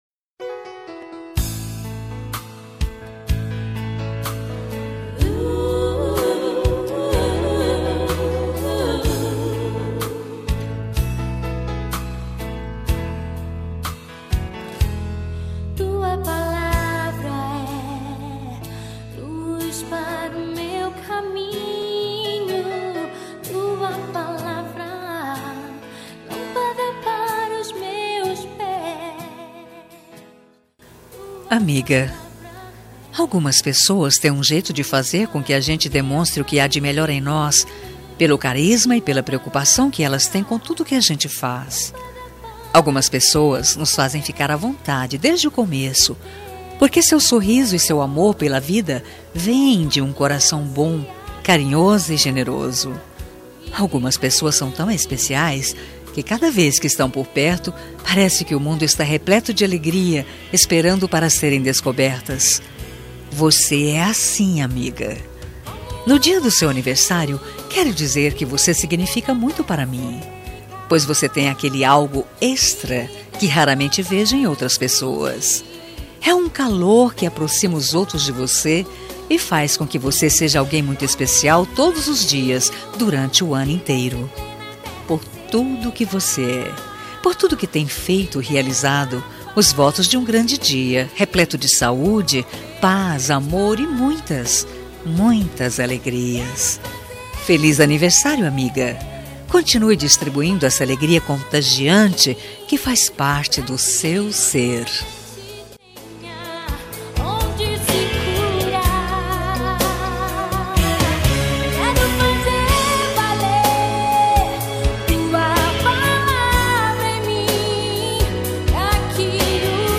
Aniversário de Amiga Gospel – Voz Feminina – Cód: 6009